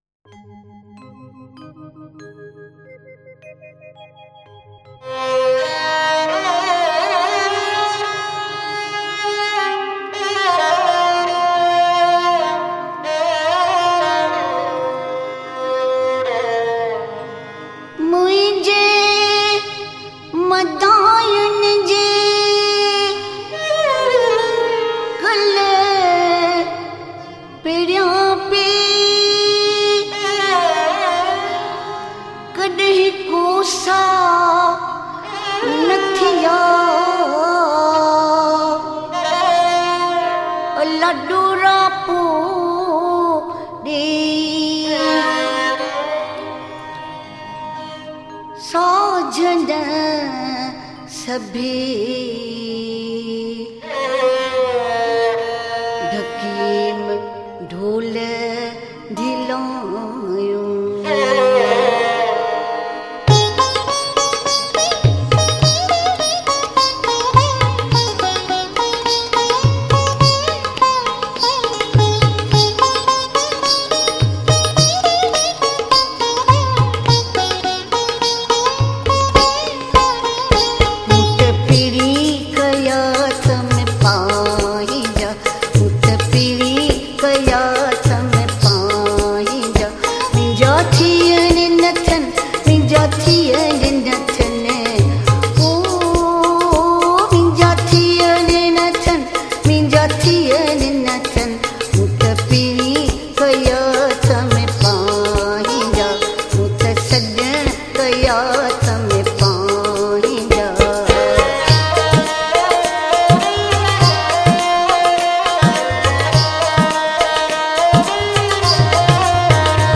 Sindhi Bhajans